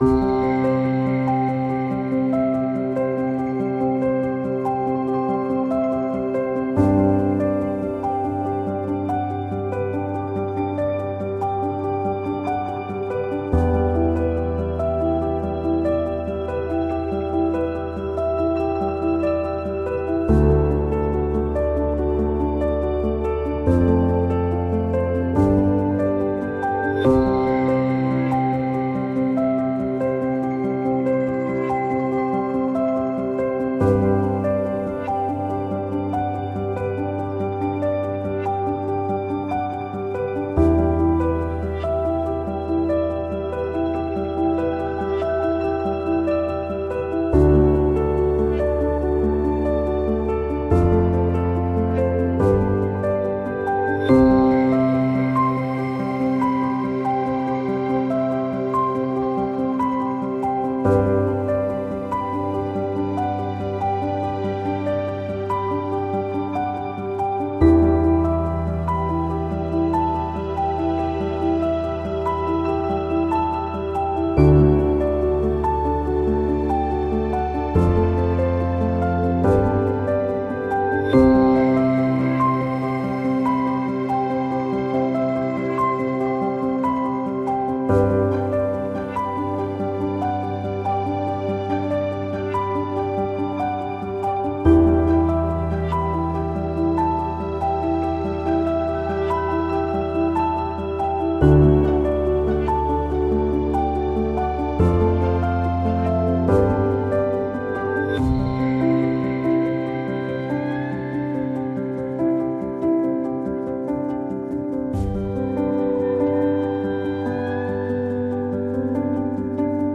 Genre: filmscore, newage.